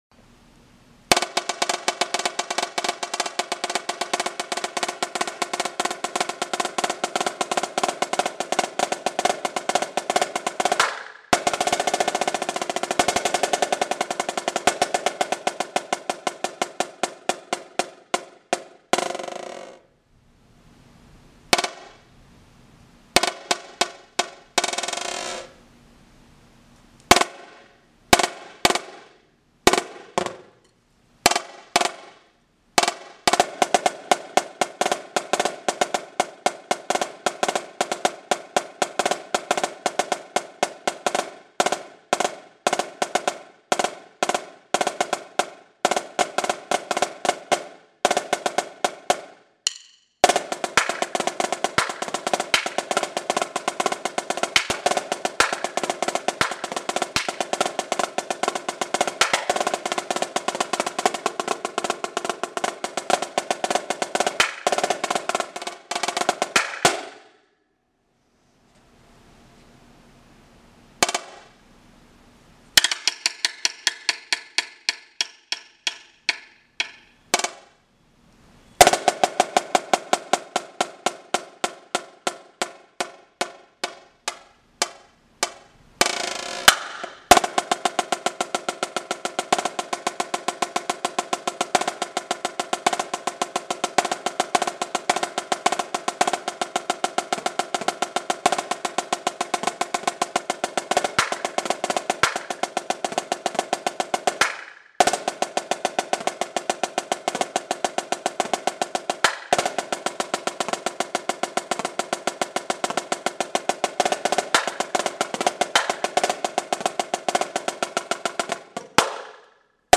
Genre: Solo Snare Drum